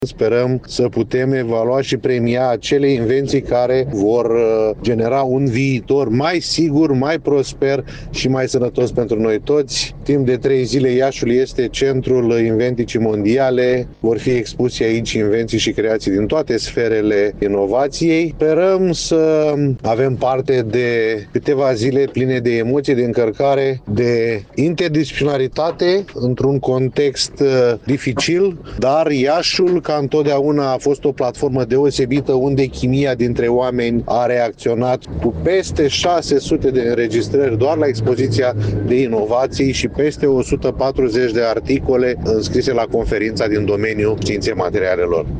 Într-o declarație pentru postul nostru de radio